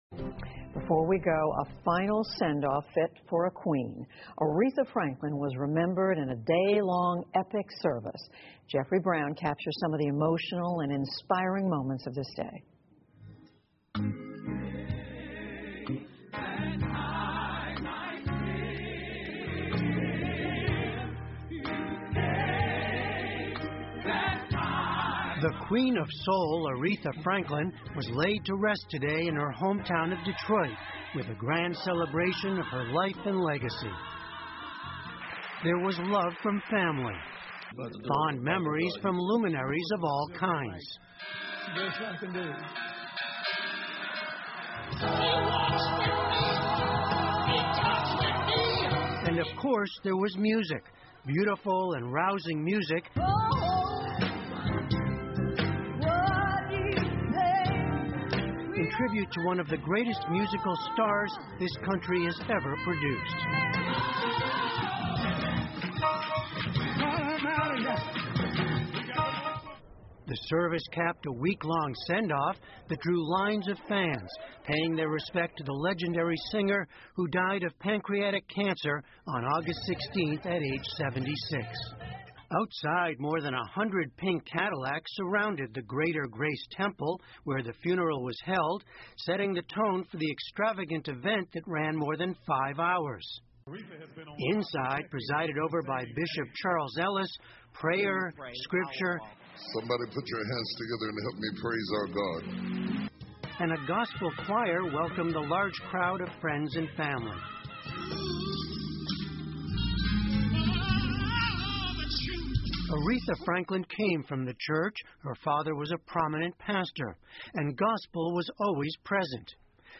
PBS高端访谈:灵魂歌者艾瑞莎·弗兰克林逝世 听力文件下载—在线英语听力室